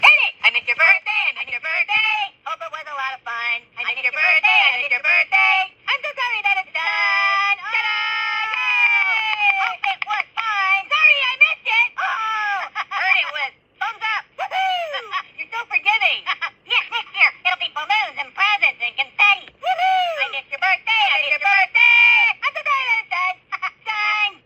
greeting card with sound
Card sound